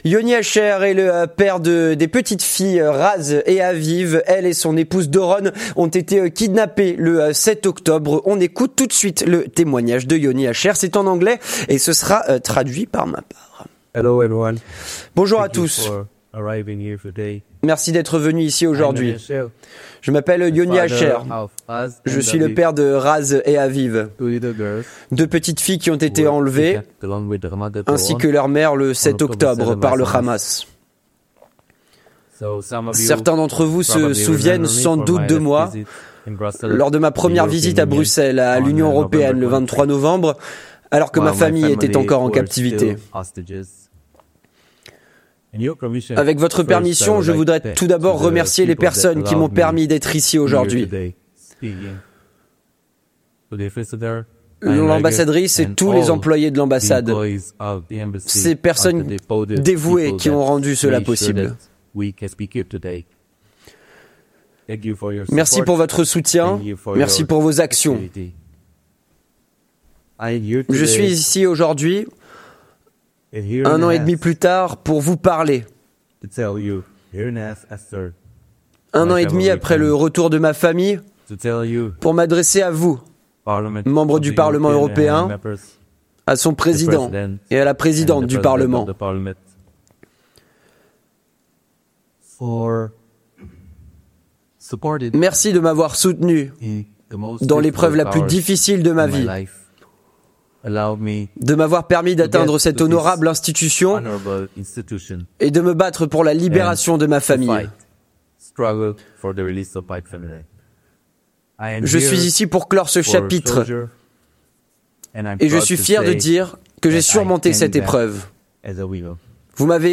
Témoignage traduit par